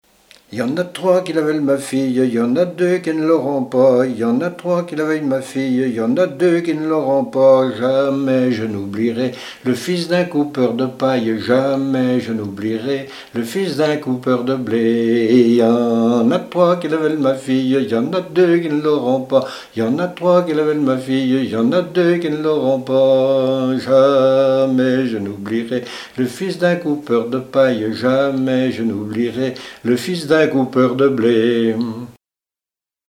Couplets à danser
danse : polka
Répertoire de chants brefs pour la danse